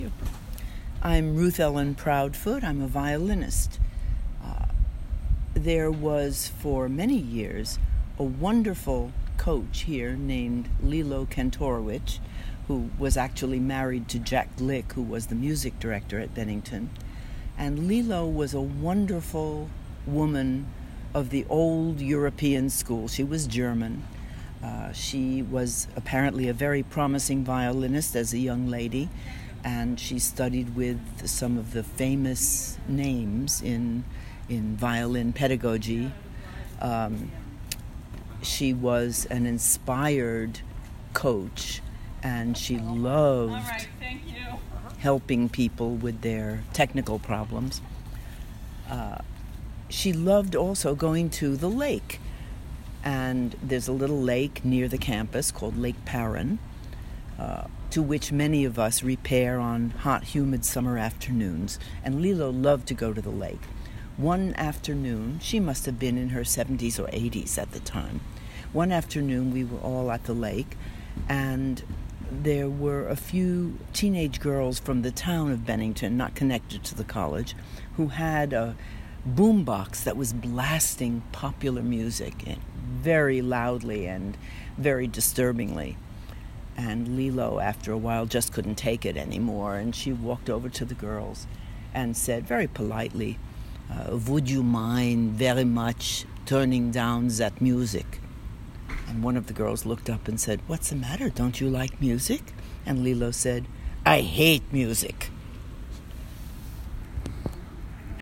CMC Stories was initiative to collect oral histories from the CMC community.